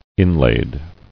[in·laid]